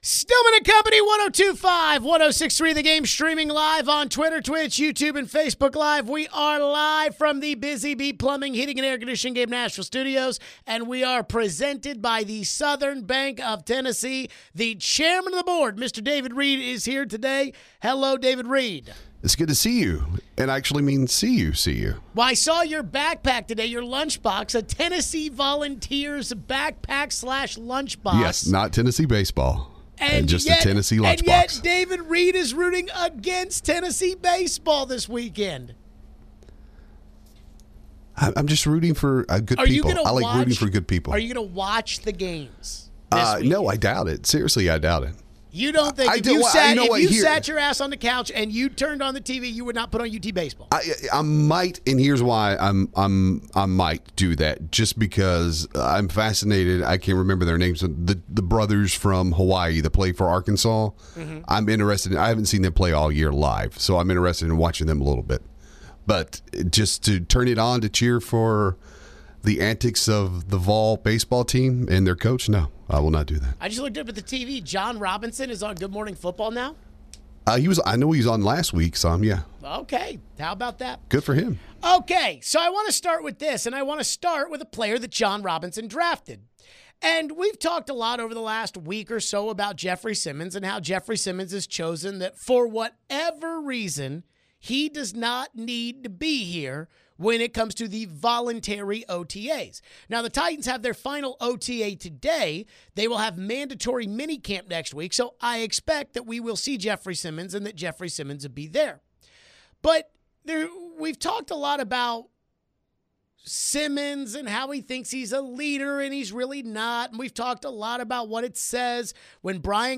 Forever Titan Chris Sanders joins the show to share his perspective on Simmons.